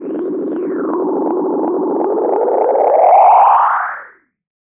Filtered Feedback 07.wav